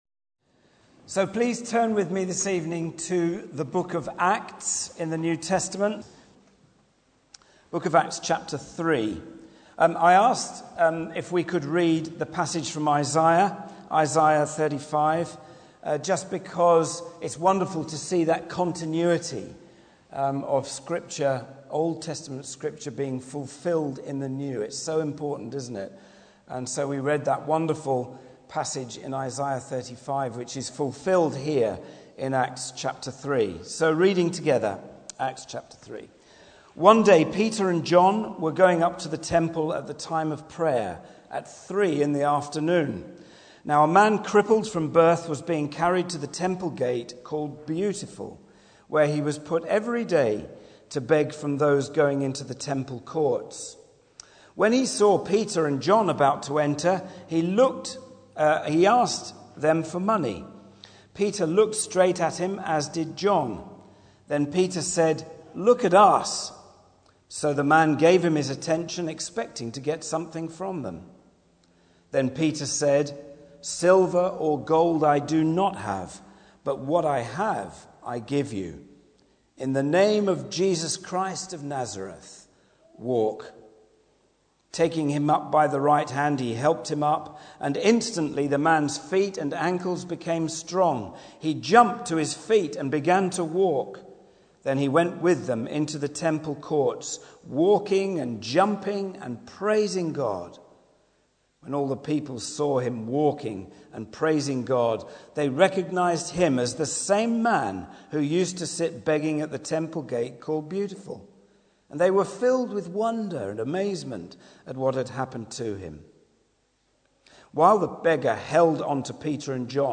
Bible Text: Acts 3 | Preacher